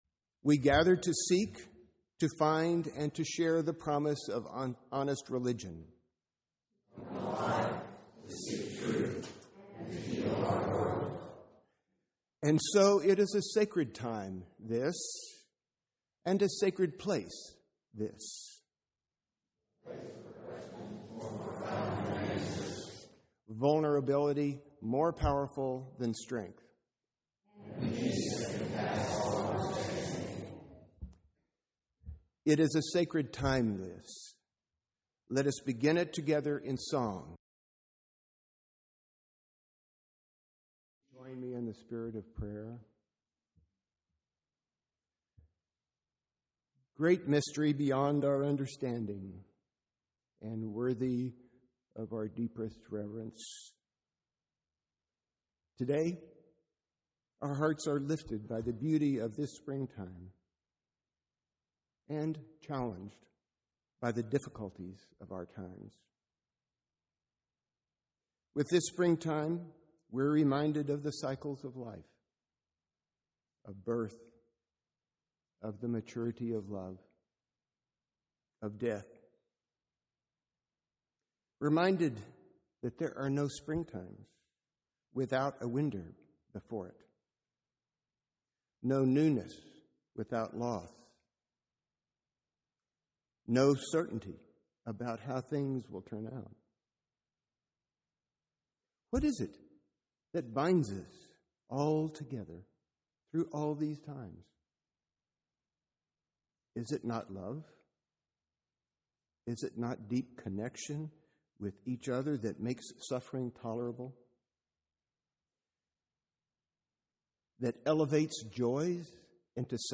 2009 Text of the sermon is not available but you can listen by clicking the play button.